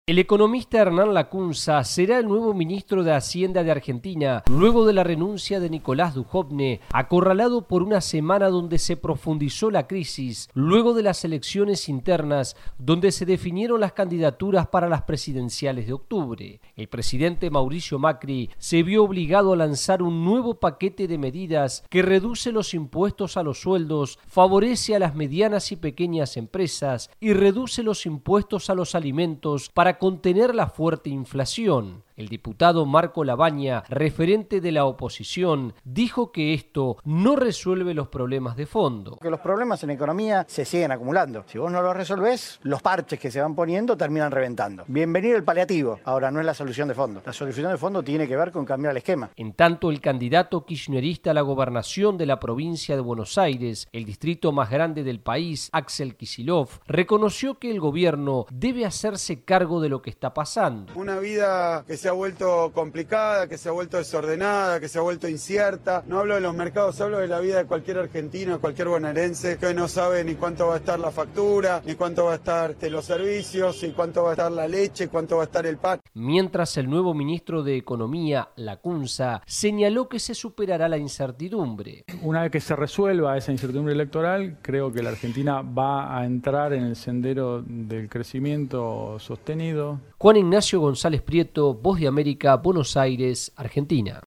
VOA: Informe desde Argentina